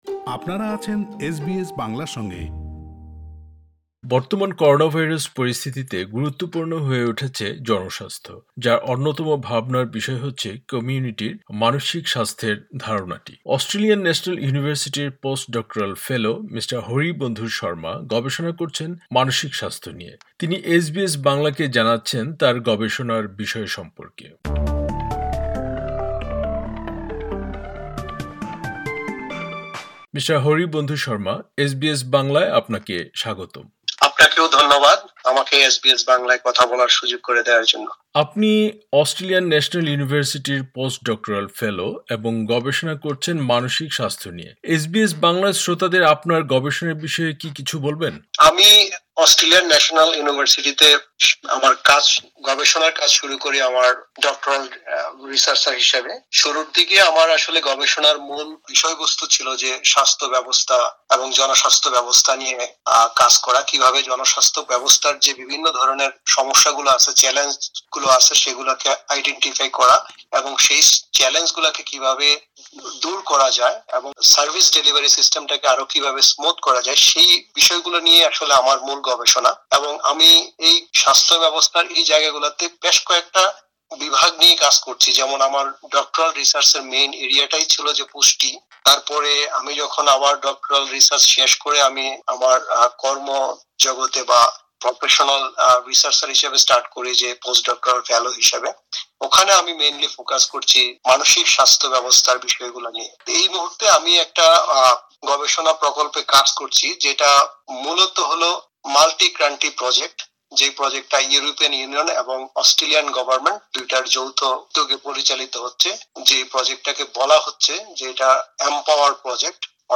পুরো সাক্ষাতকারটি